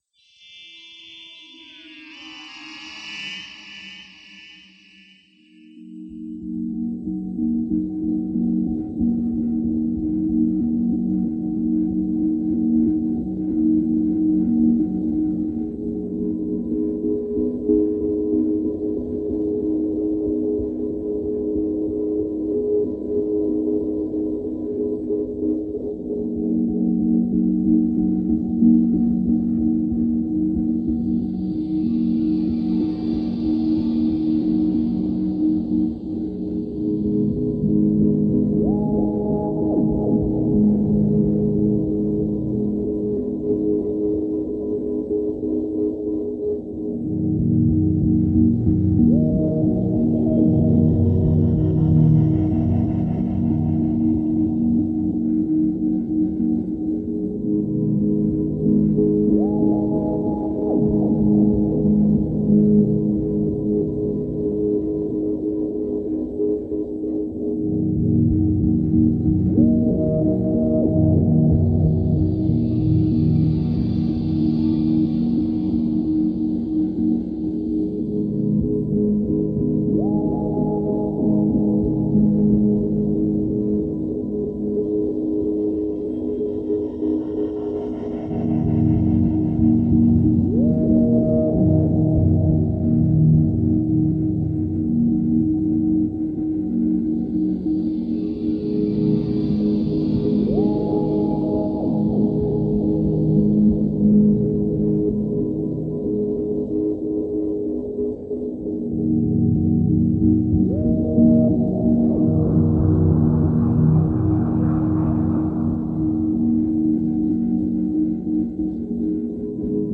Dubby, hypnotic ambient with a sense of hope and being.